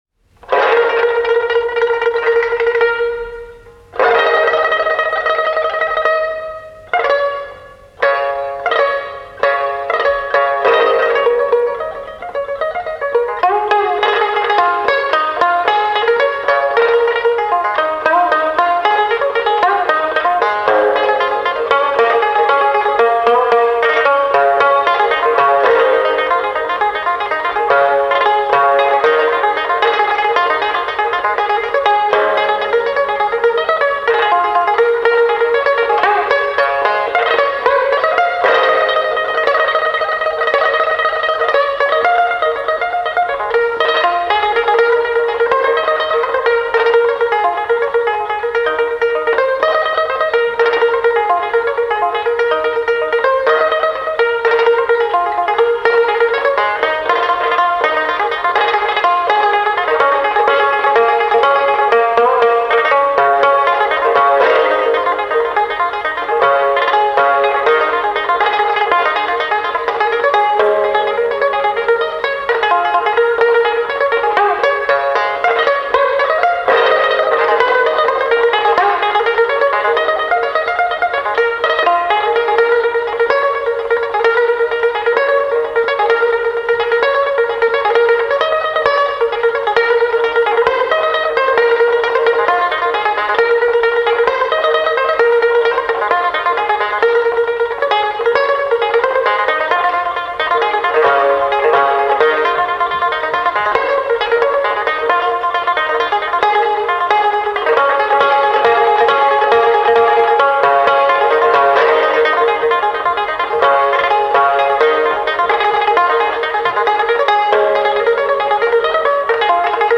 音乐类型: 民乐